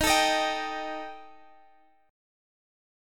Listen to D#Mb5 strummed